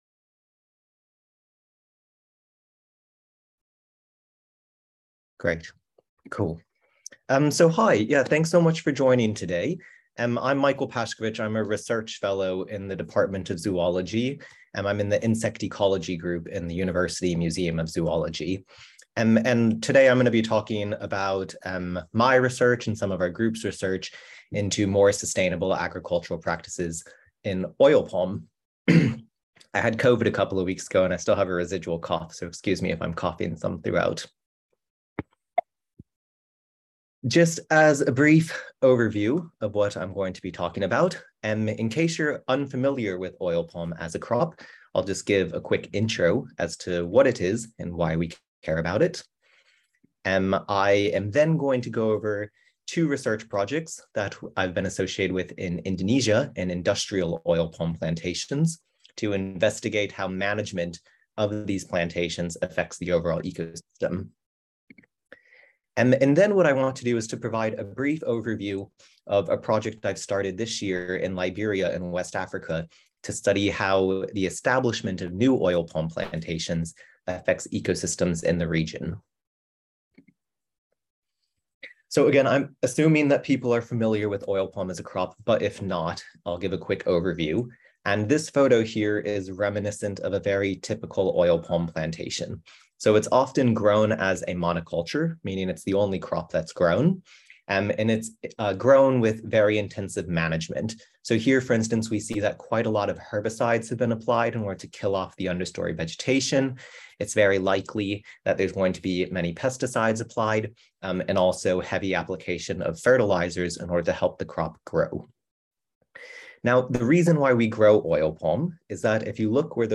Video & Audio: Global Food Security Coffee Break Seminar: Oil Palm: from Indonesia to Liberia' - Metadata